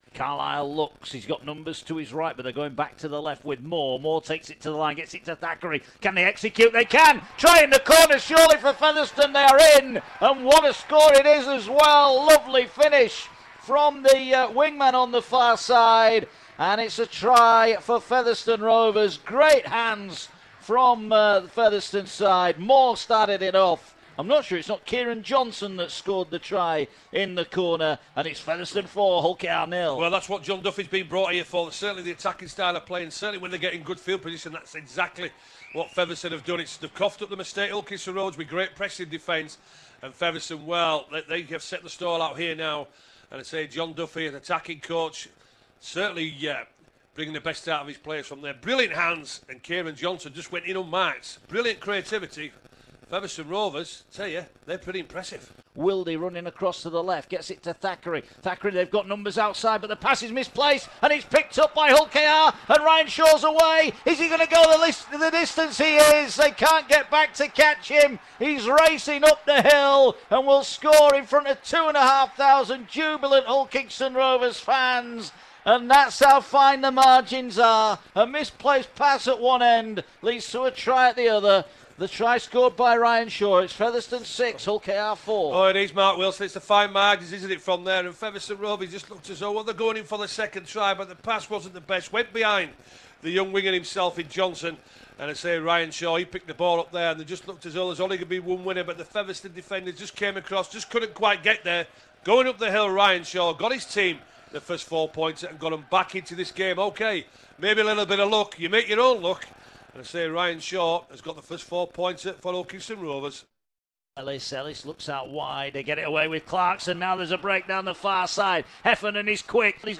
Hull KR made it four wins from four in The Qualifiers with a 30-18 win at Featherstone. Commentary